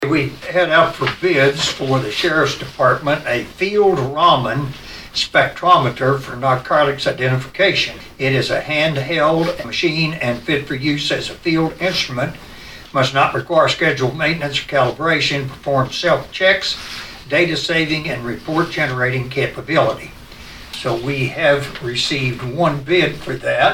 Southern District Commissioner Monte Fenner explained.